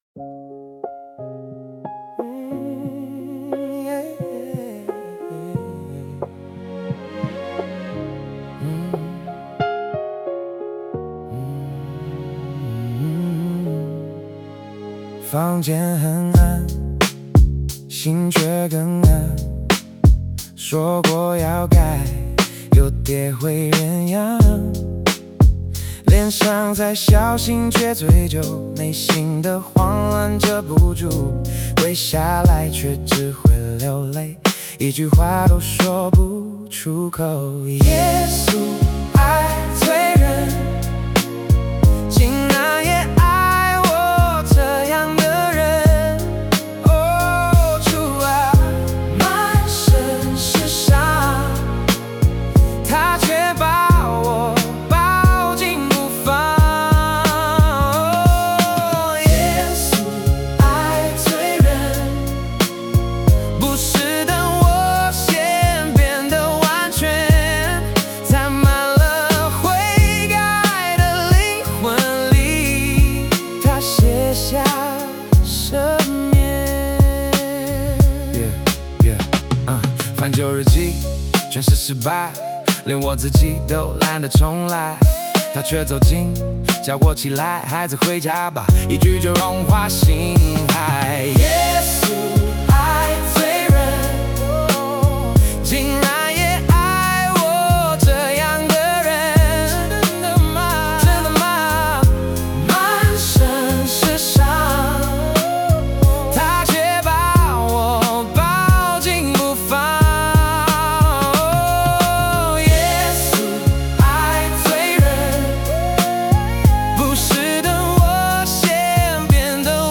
(Hip hop)